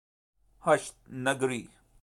pronounce) is a neighbourhood of Peshawar city in the Khyber Pakhtunkhwa province of Pakistan.